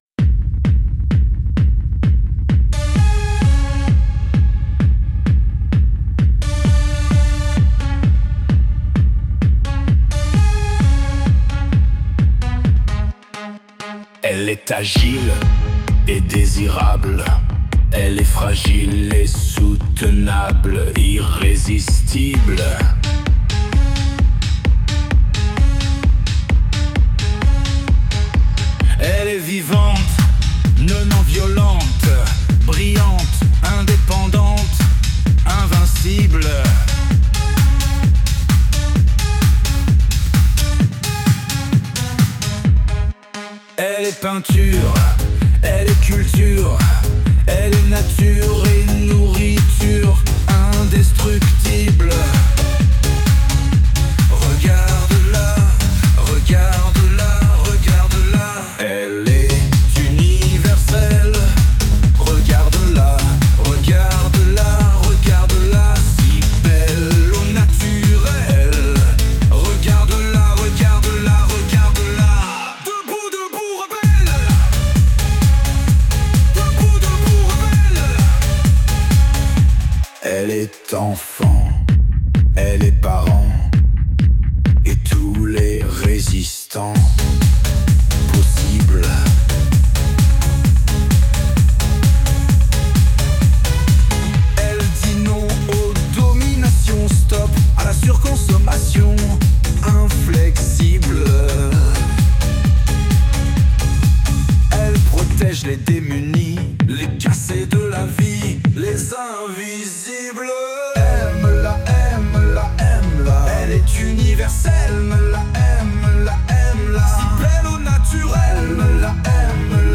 Elle-est-rebelle-Hymne-Chanson-Techno-Mars-2025.mp3